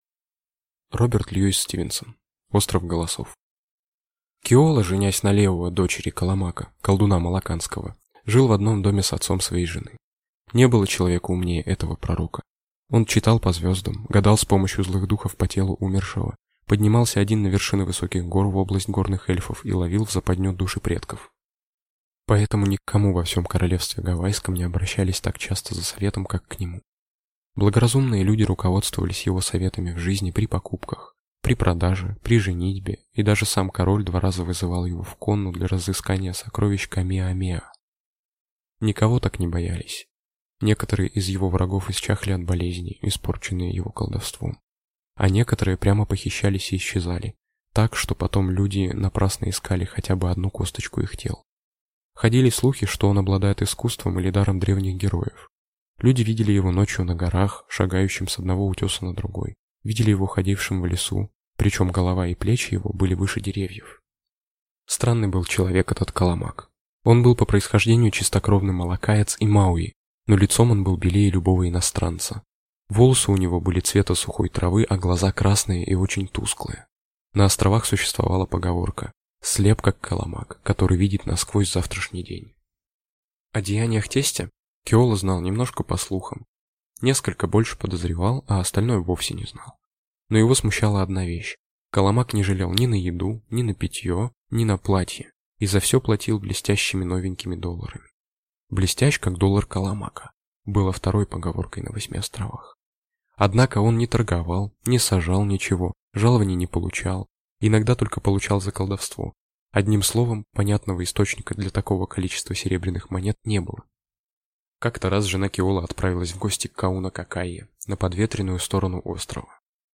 Аудиокнига Остров Голосов | Библиотека аудиокниг
Aудиокнига Остров Голосов Автор Роберт Льюис Стивенсон